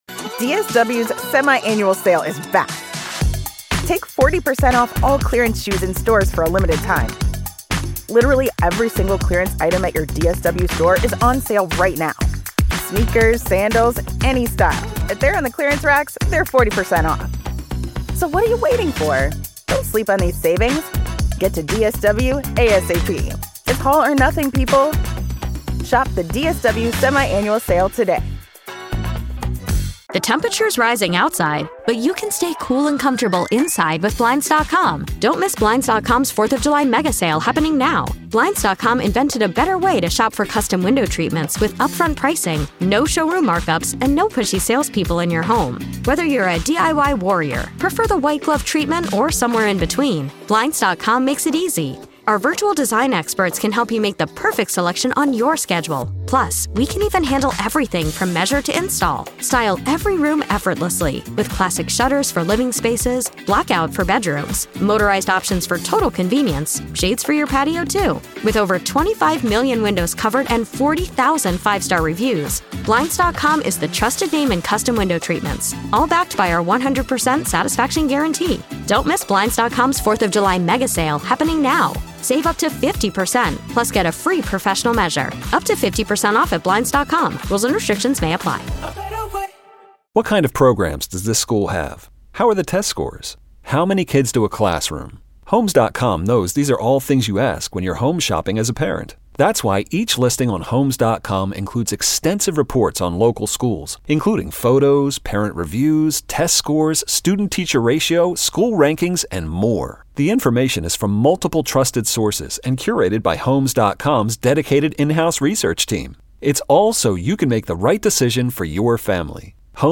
WWL is truly a cut above the rest when it comes to talk radio - because it's about YOU.